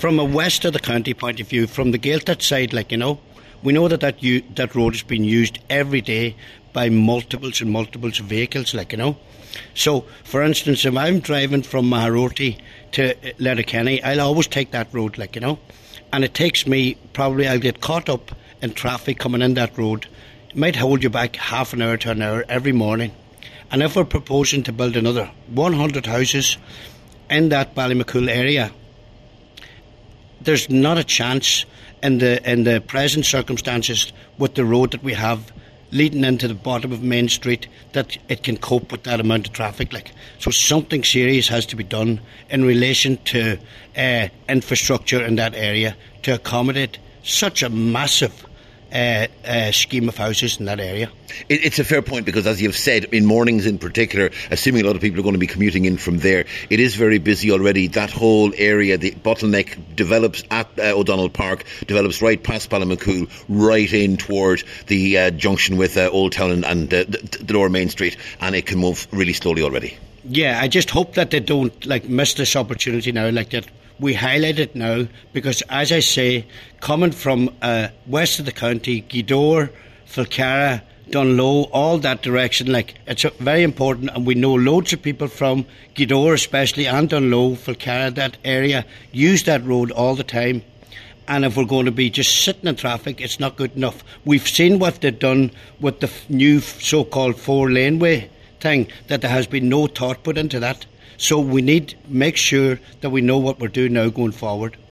Cllr Dennis McGee says motorists already face lengthy delays when travelling into Letterkenny from the west, and if road improvements aren’t made before the houses are built, the situation will only deteriorate: